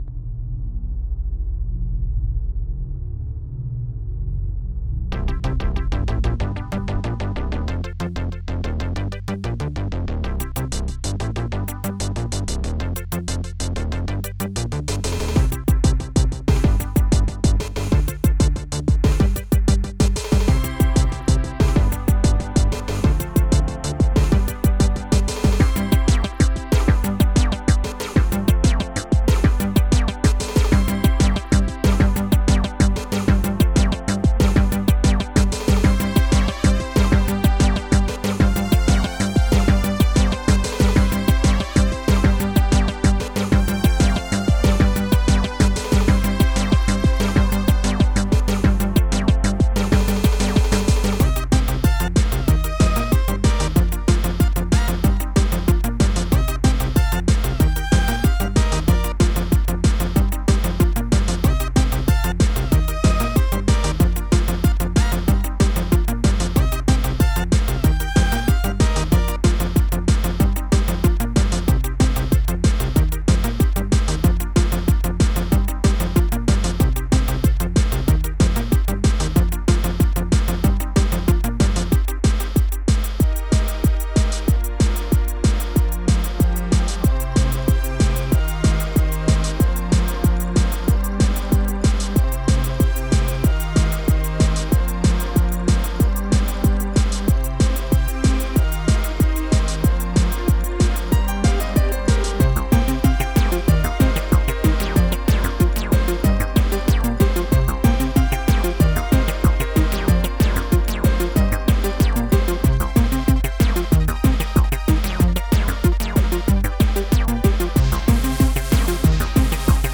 ult (UltraTracker)
AAACHOIR
PIANO-H.SMP
ACIDBASE